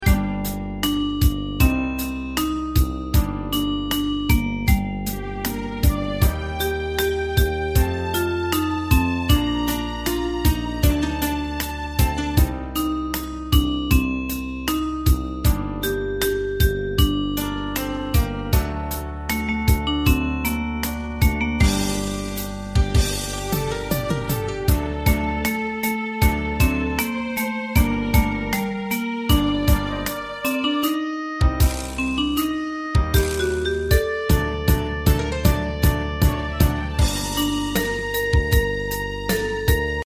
カテゴリー: ユニゾン（一斉奏） .
歌謡曲・演歌